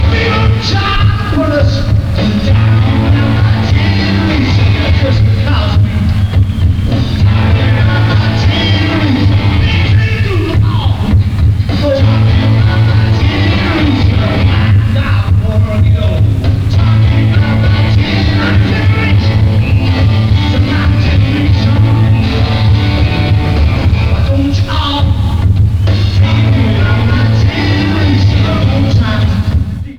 Format/Rating/Source: CD - D- - Audience
Comments: Poor sound quality.
Sound Samples (Compression Added):